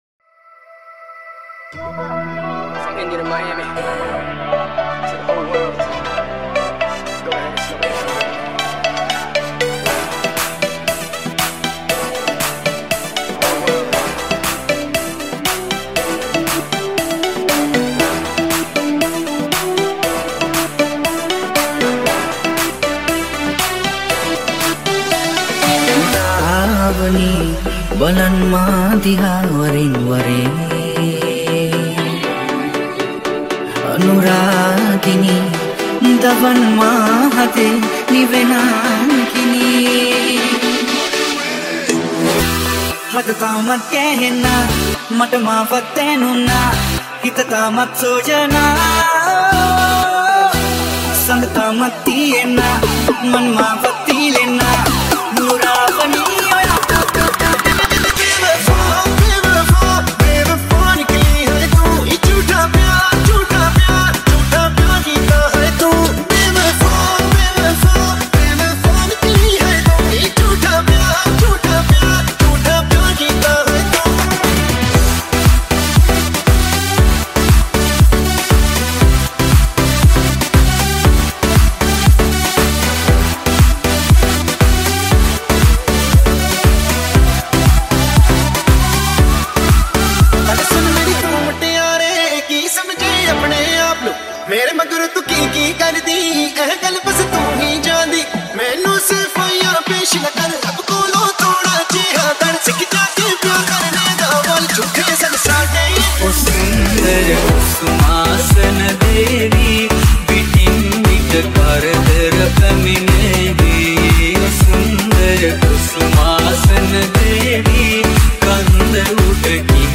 Party DJ